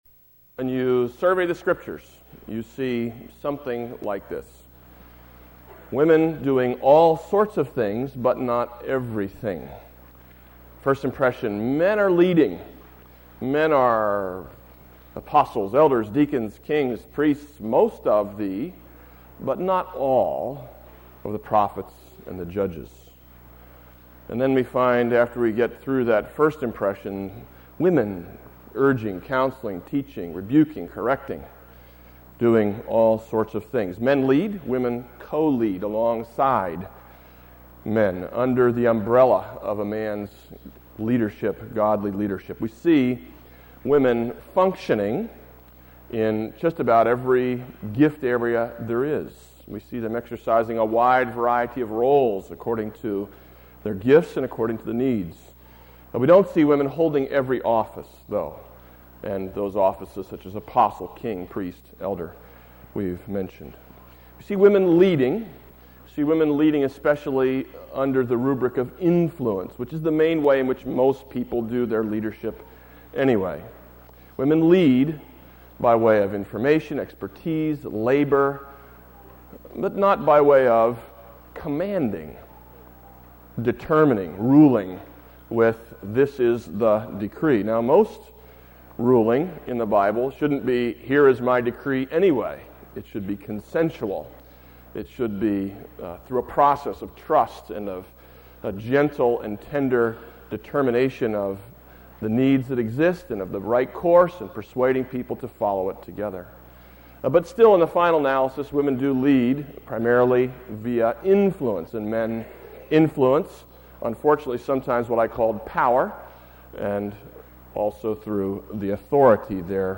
Audio Link/Title : New Testament Teaching Regarding Women's Roles Note: the audio lecture ends abruptly.